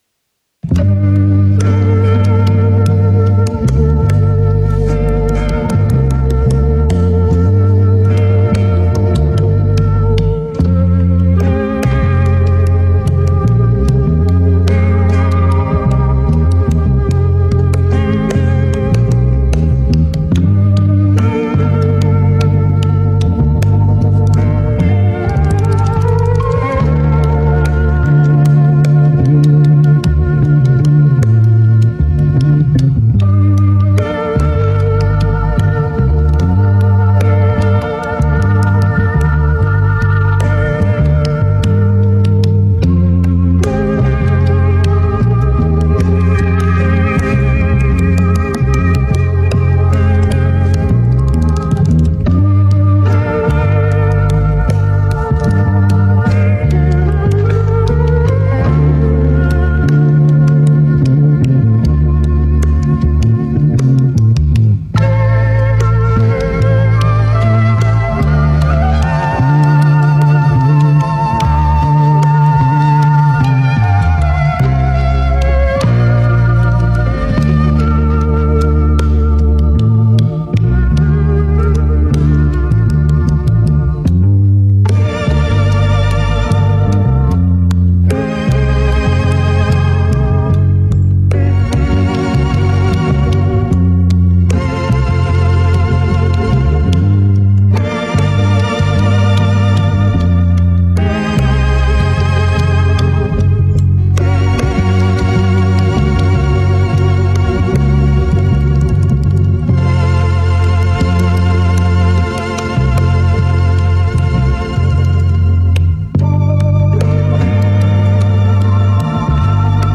BPM78
NOTABbm
MOODLo-Fi
GÉNEROBoom Bap